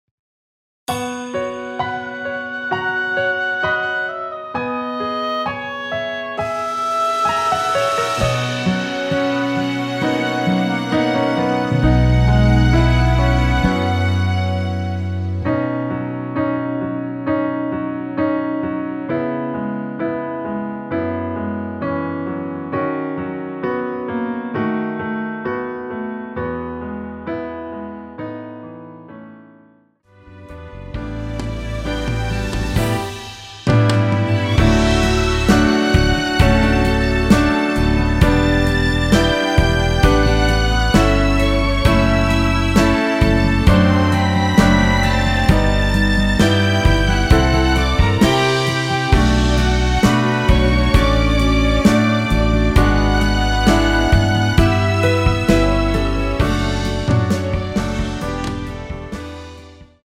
앞부분30초, 뒷부분30초씩 편집해서 올려 드리고 있습니다.
곡명 옆 (-1)은 반음 내림, (+1)은 반음 올림 입니다.